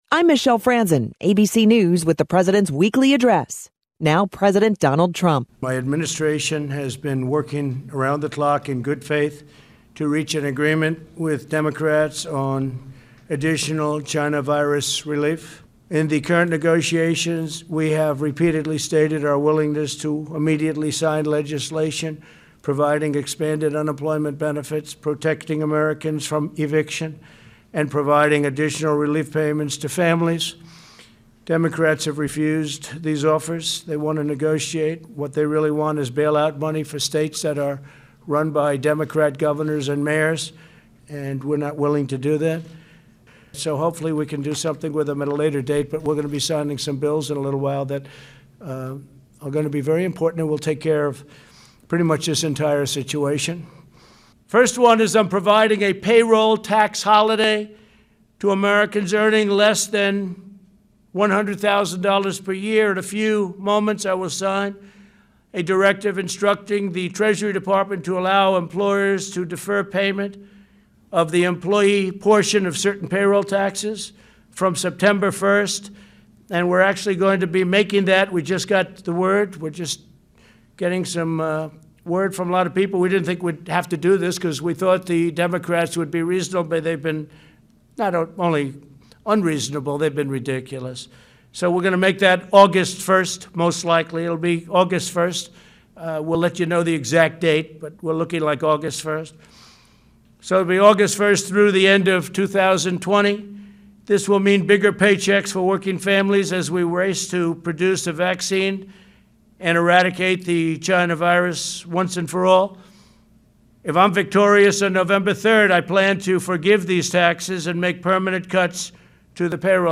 Trump was Monday’s KVML “Newsmaker of the Day”. Here are his words: